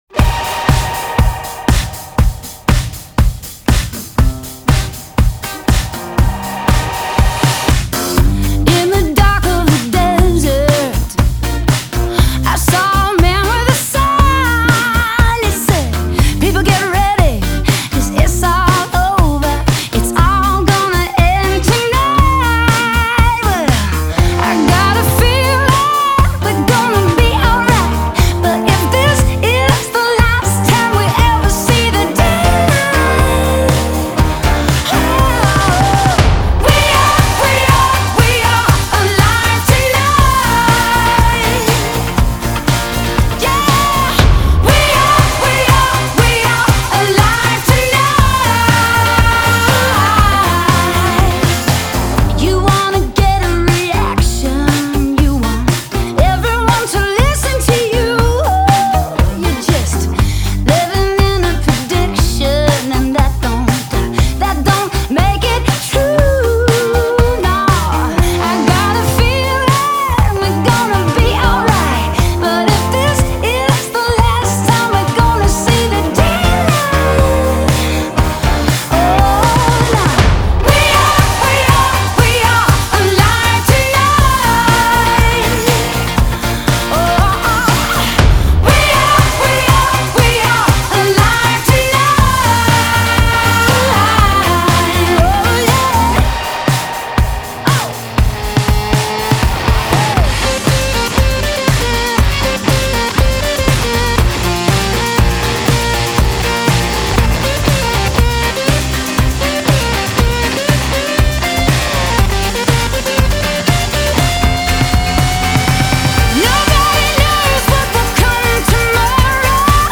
Genre: Pop Rock